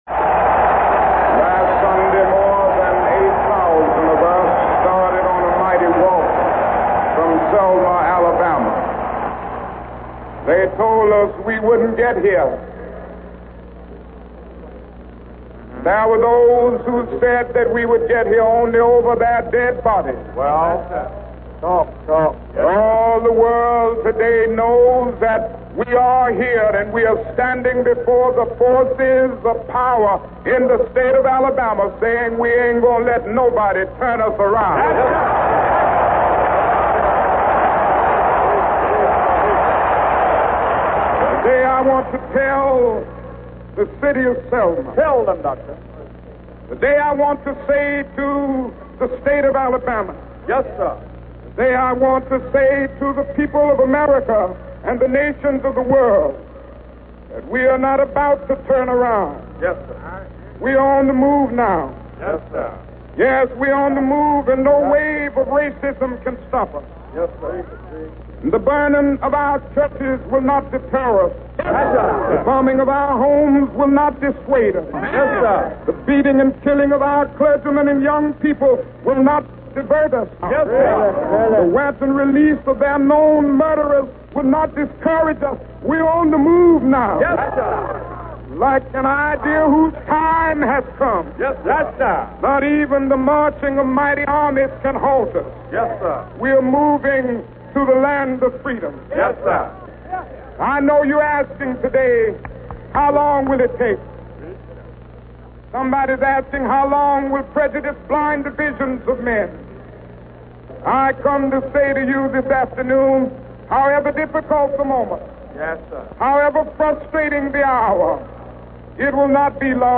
The March began two days later and picked up huge popular and celebrity support along the way, until finally on 3/25 they reached the Courthouse where they assembled to listen to Dr. King deliver a rousing and finely moral speech before they finally went into the Courthouse to register to vote.
From the above, Dr. King's speech.